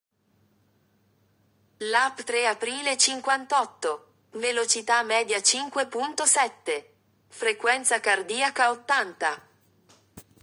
Avvisi audio